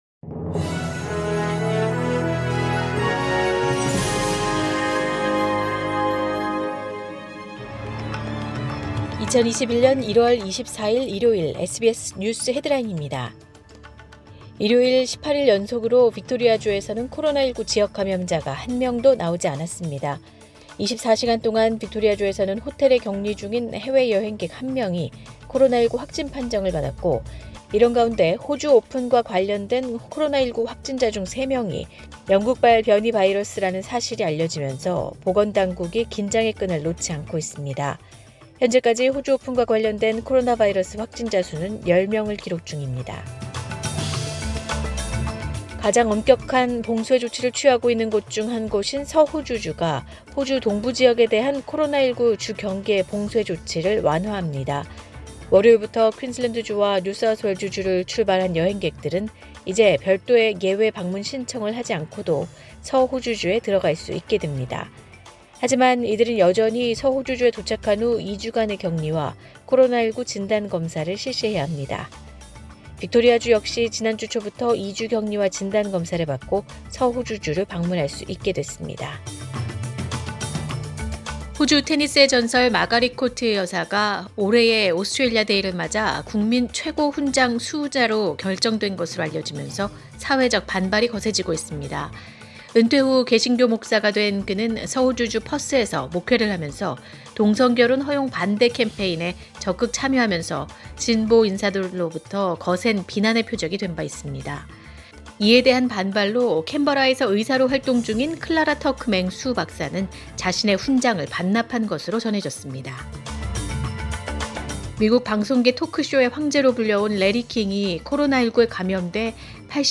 2021년 1월 24일 일요일 SBS 뉴스 헤드라인입니다.